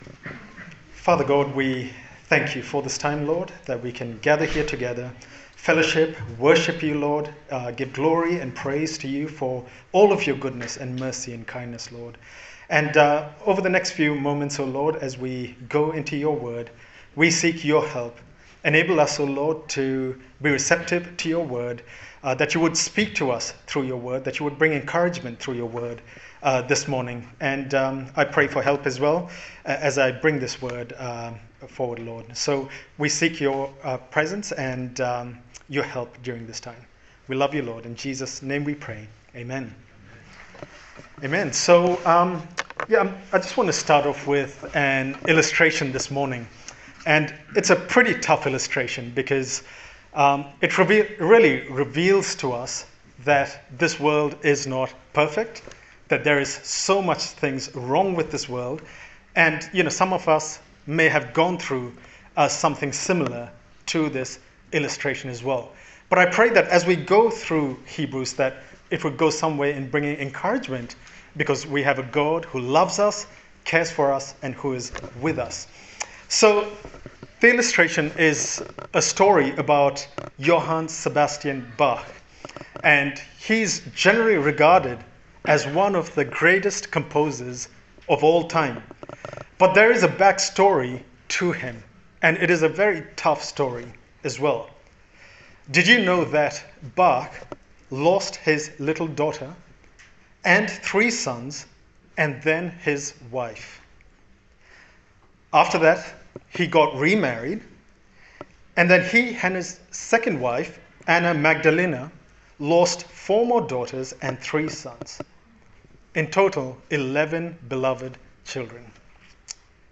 Hebrews Passage: Hebrews 2:5-18 Service Type: Sunday Service